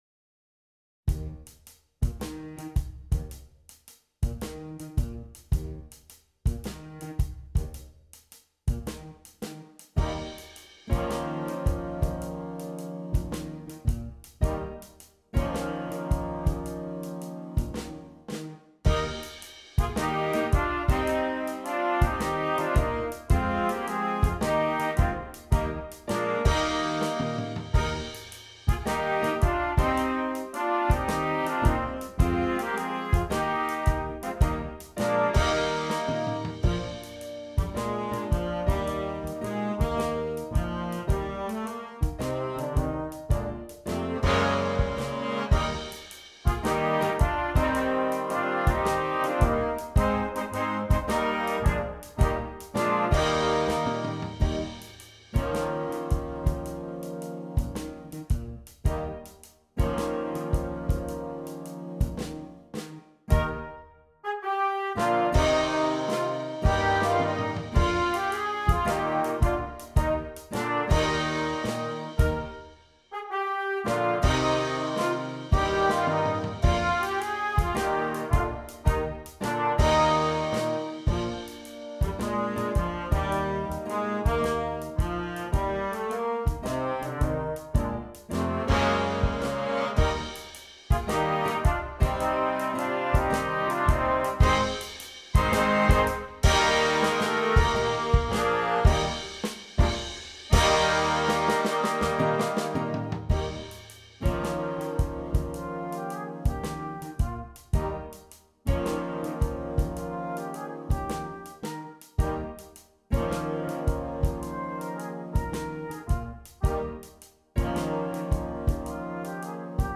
Jazz Band
Traditional Carol